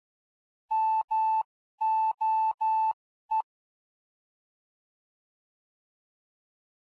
Fox hunt beacons are identified by morse code signals.
Morse code identifiers of the foxes
1 MOE — — — — — ∙ [ogg][mp3]